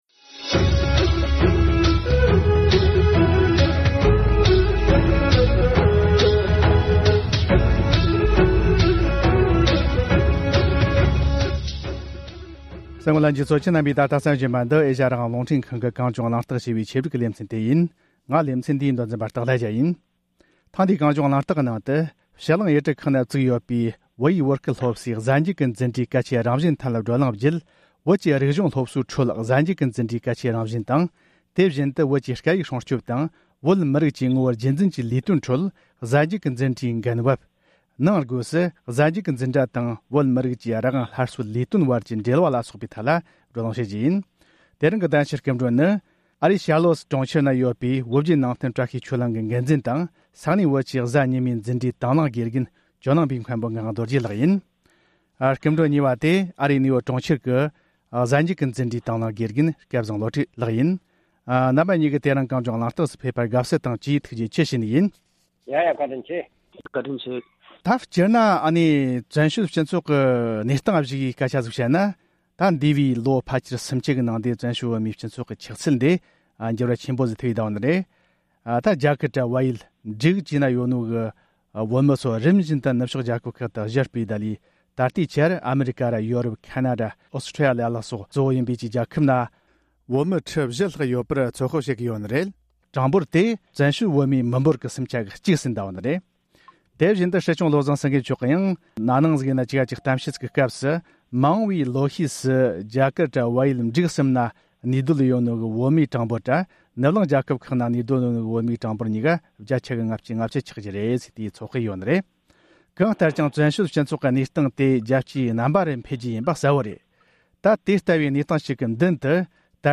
༄༅༎ཐེངས་འདིའི༼ གངས་ལྗོངས་གླེང་སྟེགས༽ ནང་དུ། ཕྱི་གླིང་ཡུལ་གྲུ་ཁག་ན་བཙུགས་ཡོད་པའི་བོད་ཡིག་བོད་སྐད་སློབ་སའི་གཟའ་མཇུག་གི་འཛིན་གྲྭའི་གལ་ཆེའི་རང་བཞིན་ཐད་བགྲོ་གླེང་བརྒྱུད།